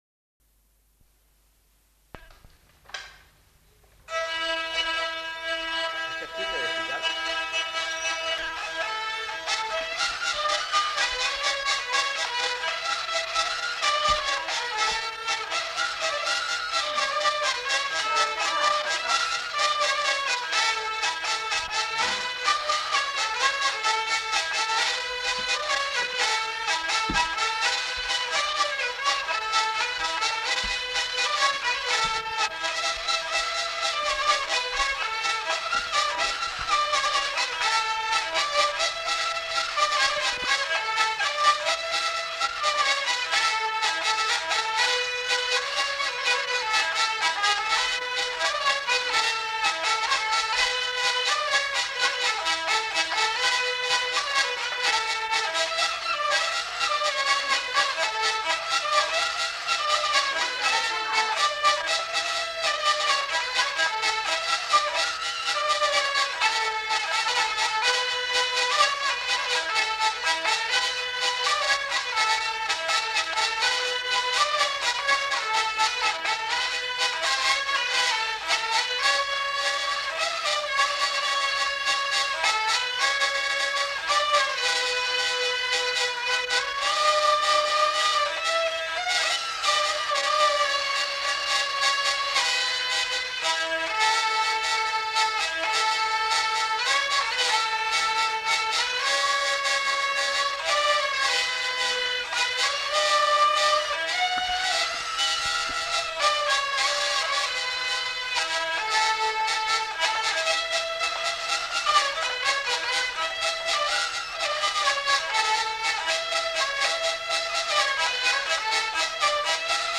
Trois airs joués à la vielle à roue
enquêtes sonores